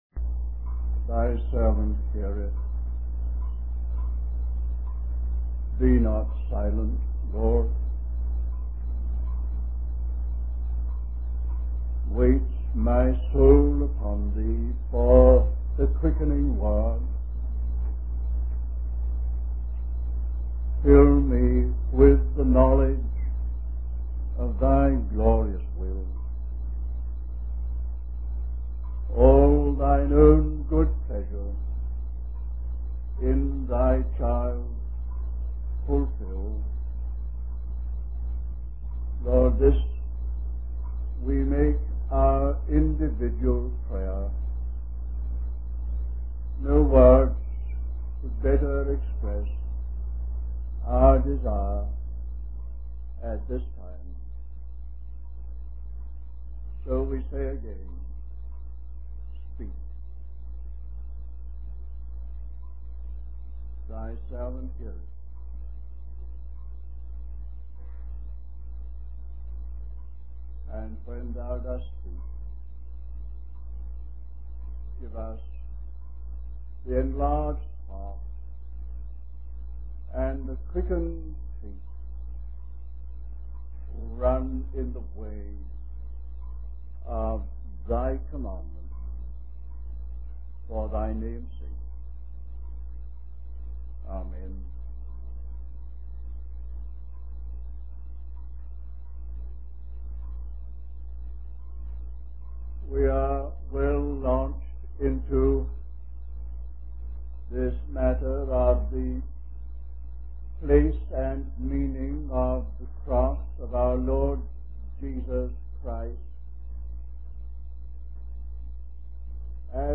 Wabanna (Atlantic States Christian Convocation)
Message